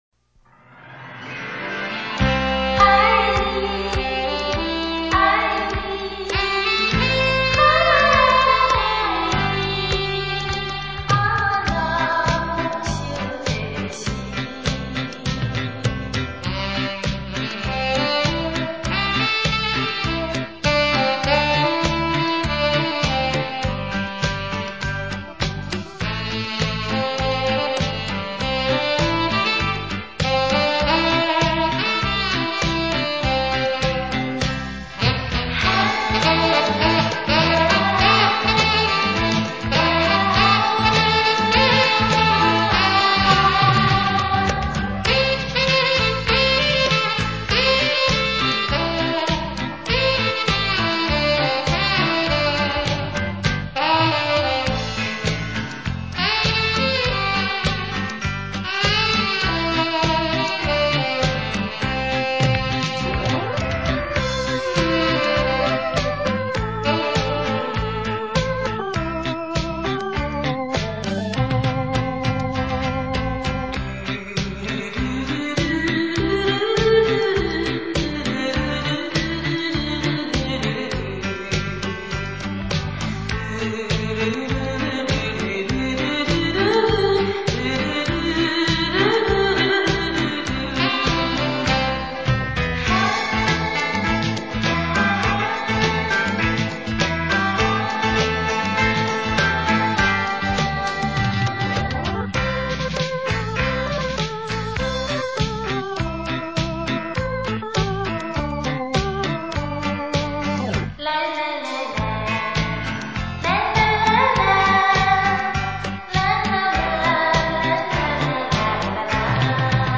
怀旧迷人的乐章